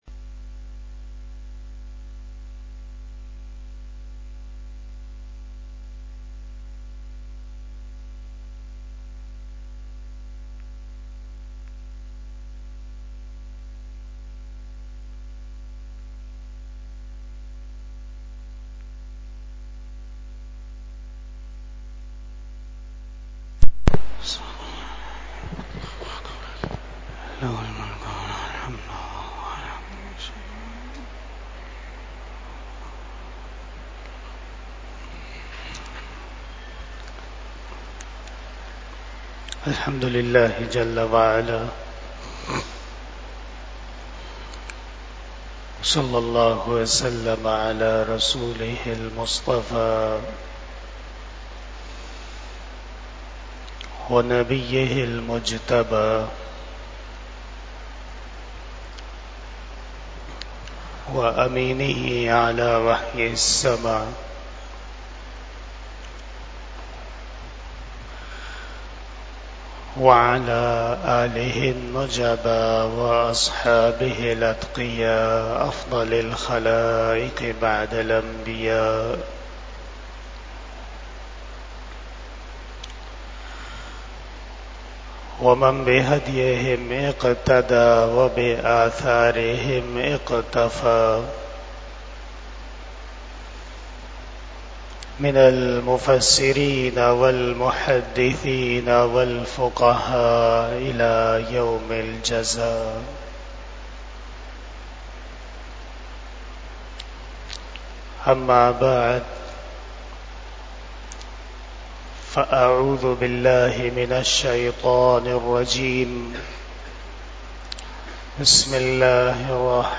38 Bayan E Jummah 20 September 2024 (15 Rabi Ul Awwal 1446 HJ)
بیان جمعۃ المبارک 15 ربیع الاول 1446ھ بمطابق 20 ستمبر 2024ء